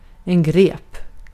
Ääntäminen
Synonyymit gaffel Ääntäminen Tuntematon aksentti: IPA: /ɡreːp/ Haettu sana löytyi näillä lähdekielillä: ruotsi Käännös Substantiivit 1. talikko Artikkeli: en .